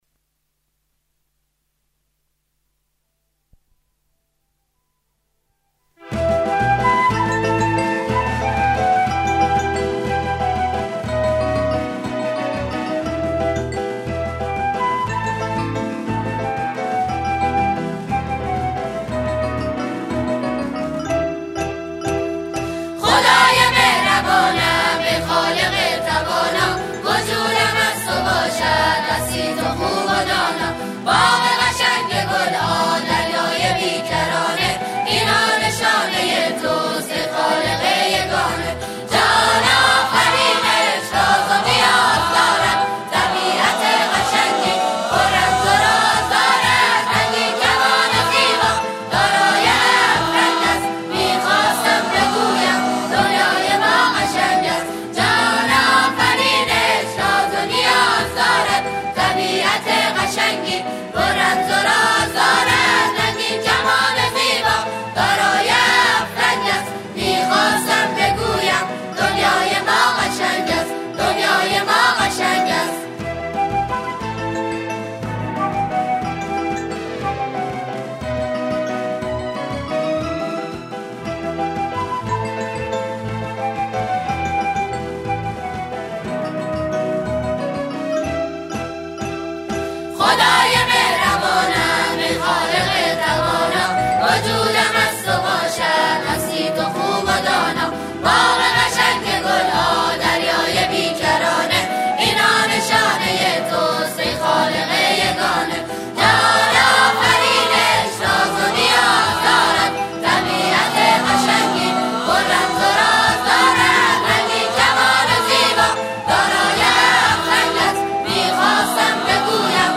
سرودهای اعیاد اسلامی